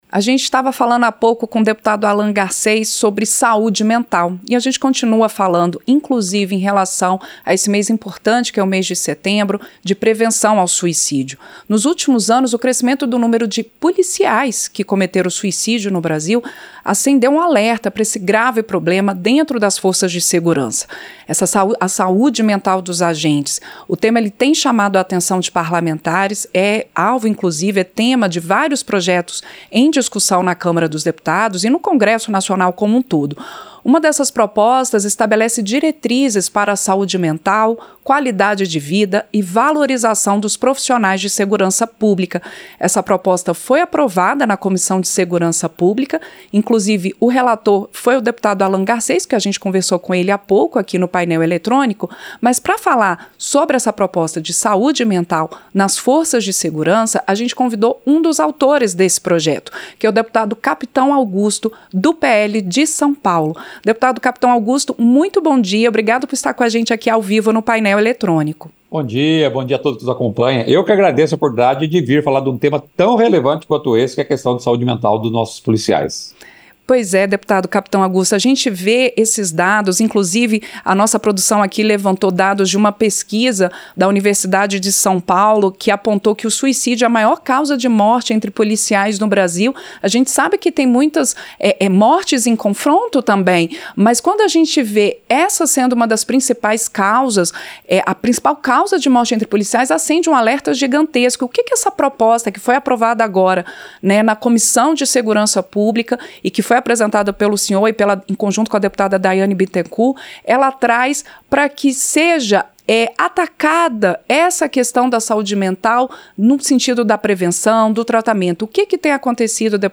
Entrevista - Dep.